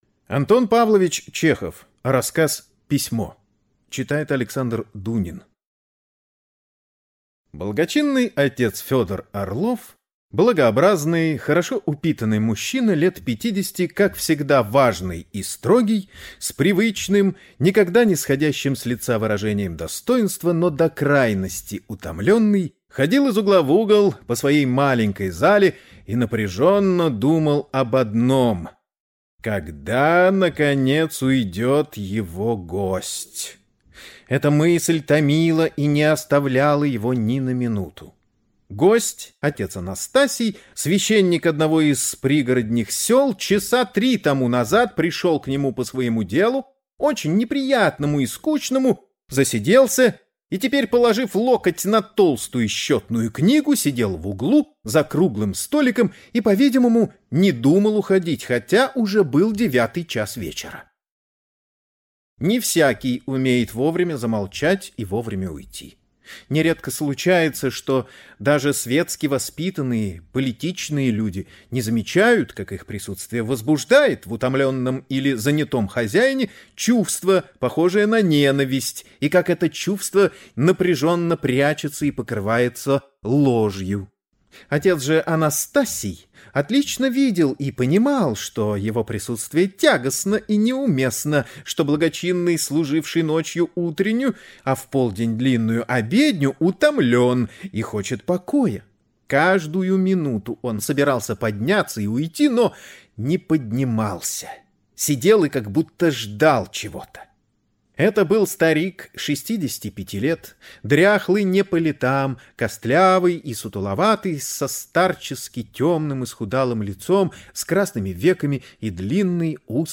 Аудиокнига Письмо | Библиотека аудиокниг